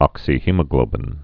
(ŏksē-hēmə-glōbĭn)